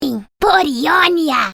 A voice clip of Kennen, the ninja character from League of Legends, speaking Brazilian Portuguese.